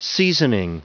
Prononciation du mot seasoning en anglais (fichier audio)
Prononciation du mot : seasoning